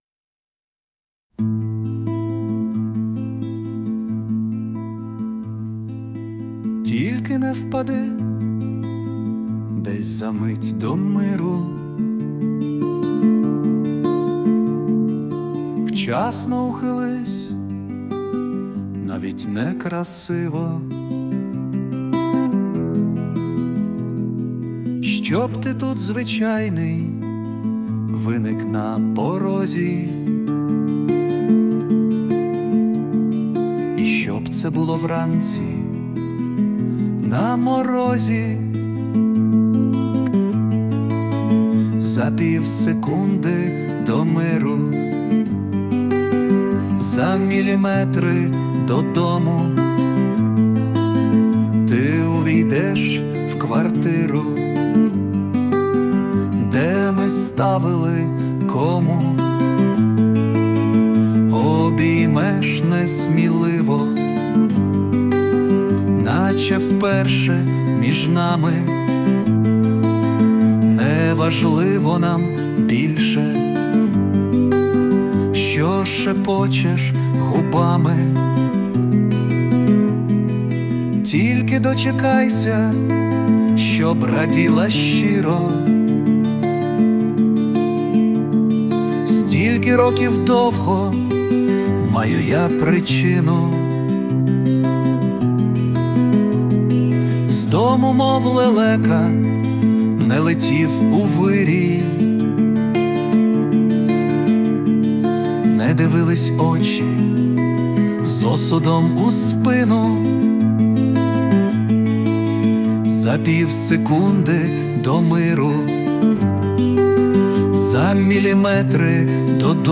Власне виконання
Перепади звуку усунуто з допомогою SUNO
СТИЛЬОВІ ЖАНРИ: Ліричний